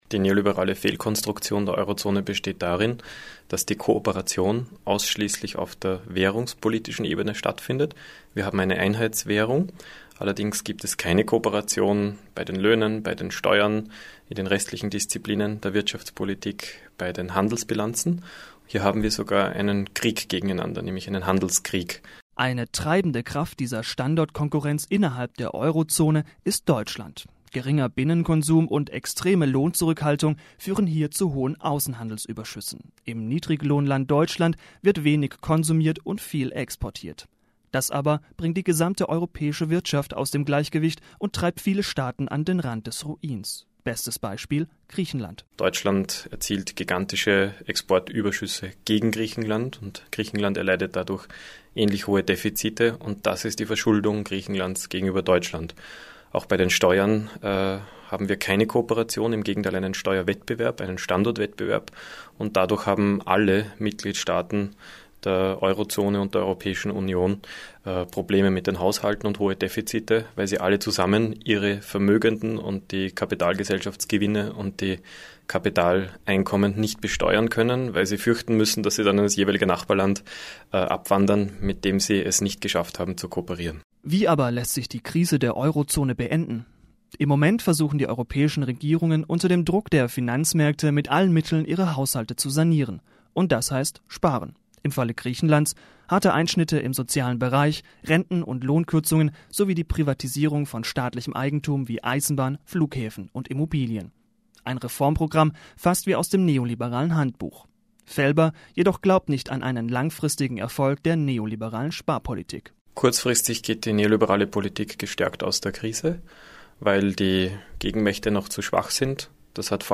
Interview mit Christian Felber (ATTAC-Österreich)zur neoliberalen (Fehl)-Konstruktion der Euro-Zone, zum Griechenland-Crash und möglichen Wegen aus der Schuldenkrise.